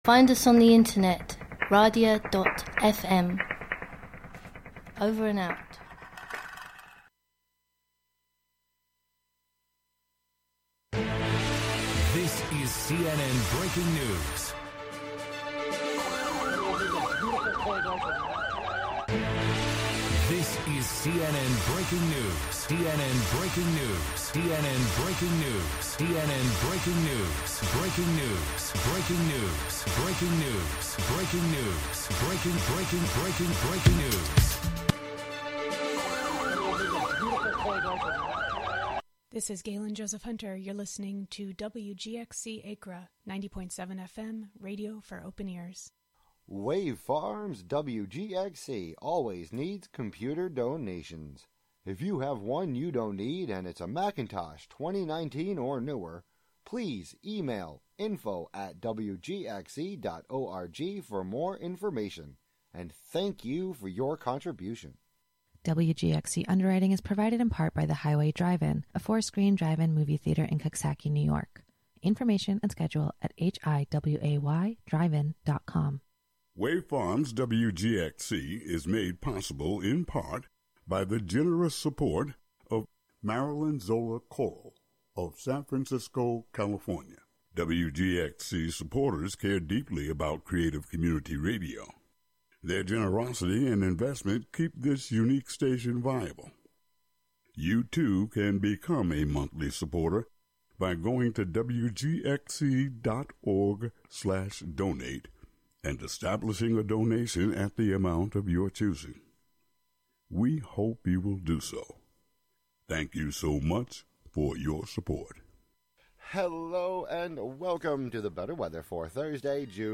In-studio / pedestrian interviews, local event listings, call-ins, live music, and other chance connections will be sought on air.